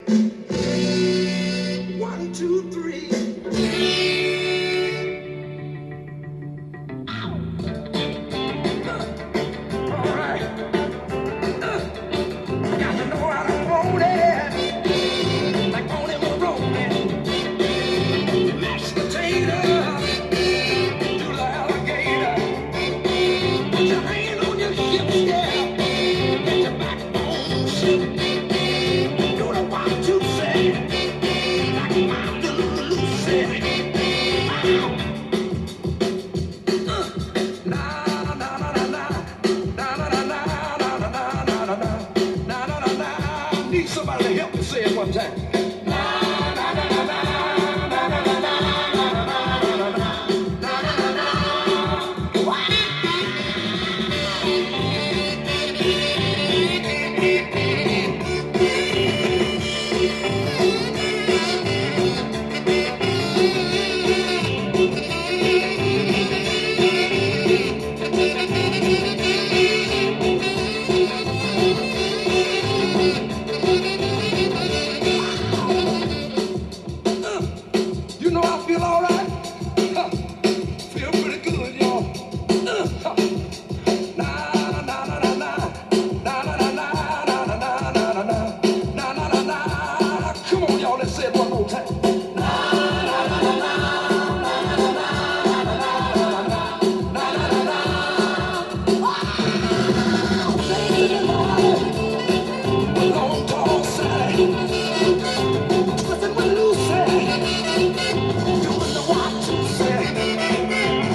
ジャンル：SOUL
店頭で録音した音源の為、多少の外部音や音質の悪さはございますが、サンプルとしてご視聴ください。